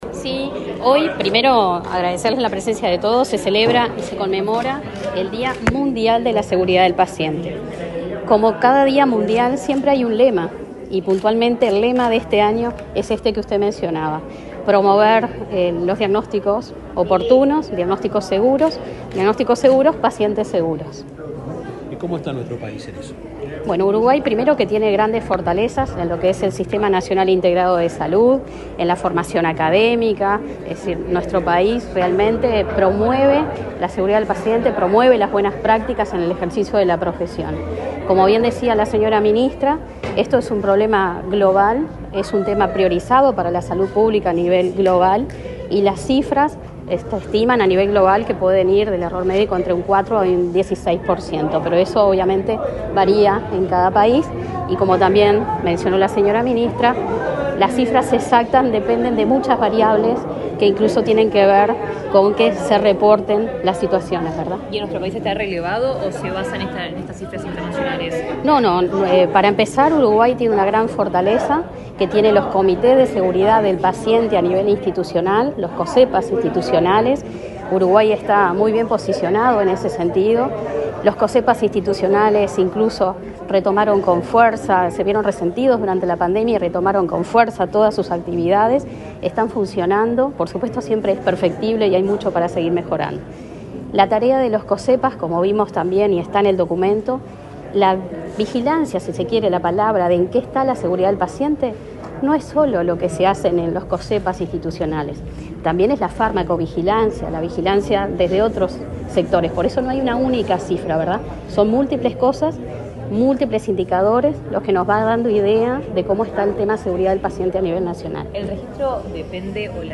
Declaraciones de la directora general de Salud, Adriana Alfonso
Declaraciones de la directora general de Salud, Adriana Alfonso 17/09/2024 Compartir Facebook X Copiar enlace WhatsApp LinkedIn En el marco del Día Mundial de Seguridad del Paciente, la directora general de Salud, Adriana Alfonso, dialogó con la prensa, luego del acto alusivo a la fecha que realizó el Ministerio de Salud Pública.